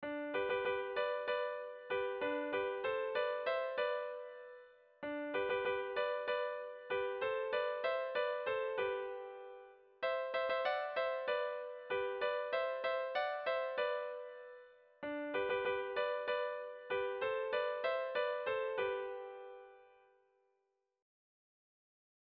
Sentimenduzkoa
Zortziko txikia (hg) / Lau puntuko txikia (ip)
A1A2BA2